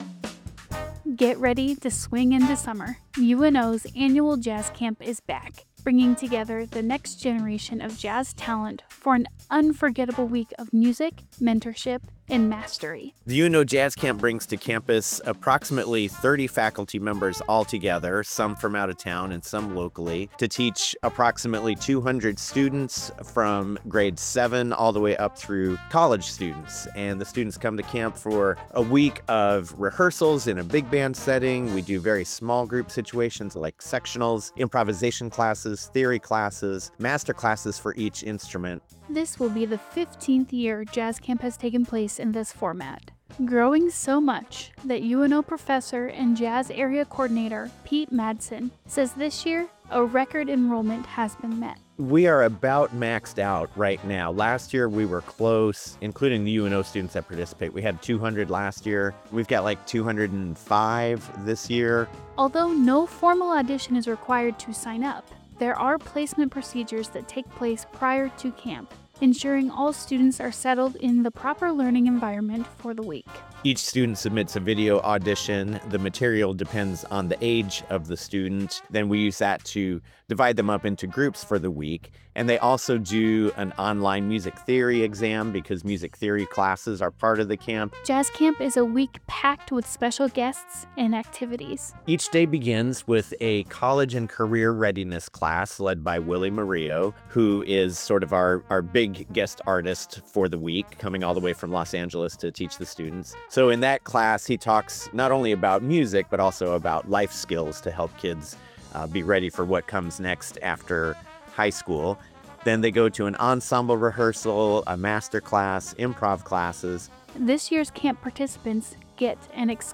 Music provided by: The Big Bad Bones